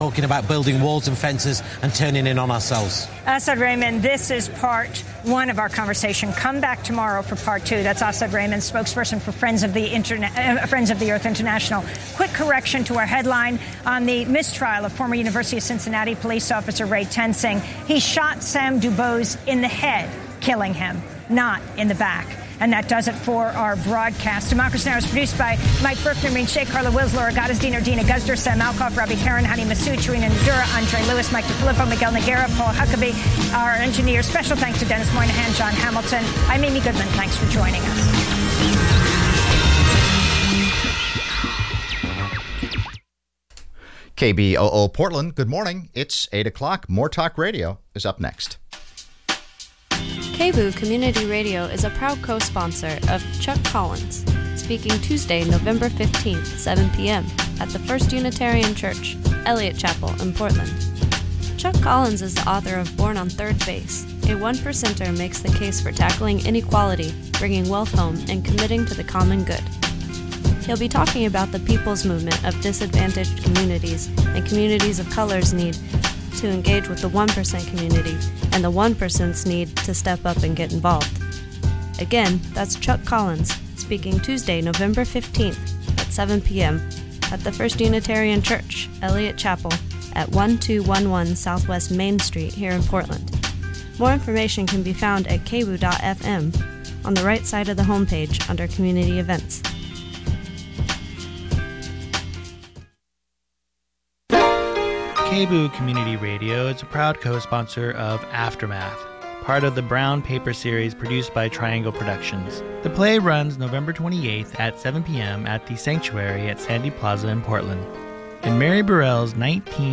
More Talk Radio